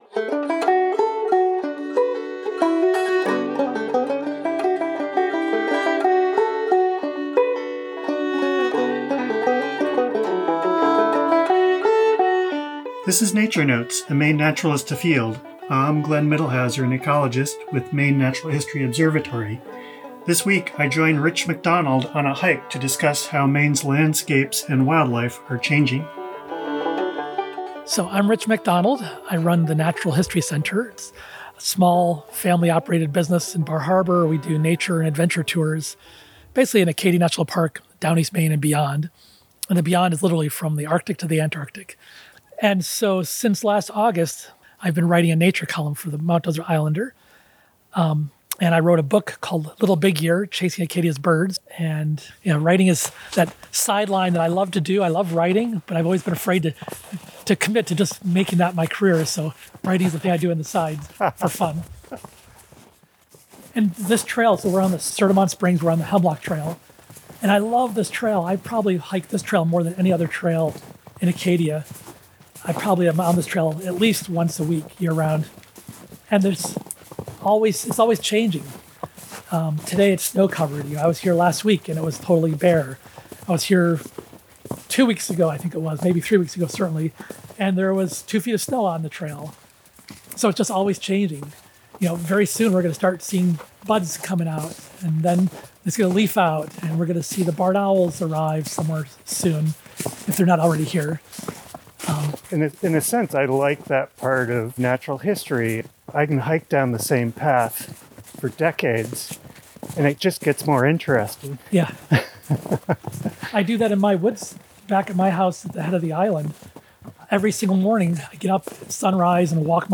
As they move through the snow-covered woods, they reflect on shifting bird communities, the subtle but telling signs of seasonal transitions and the nesting behavior of Maine owls.